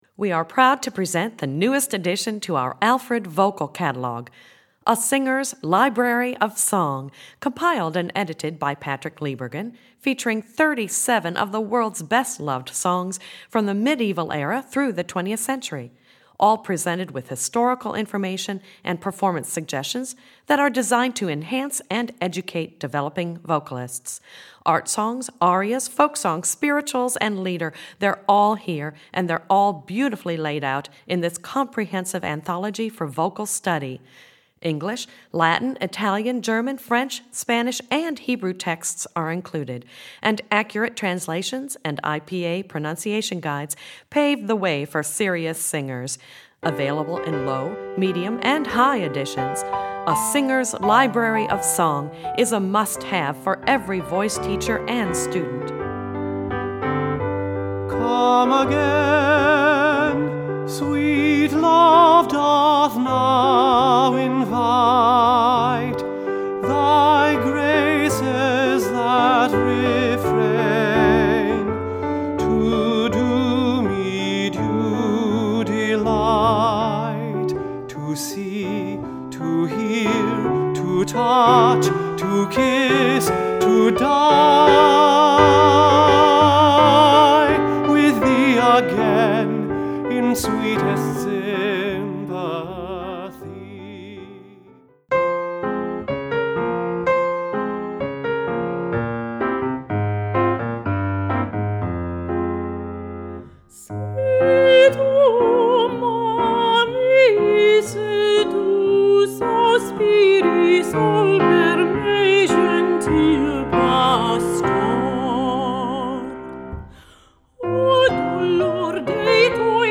Voicing: High Voice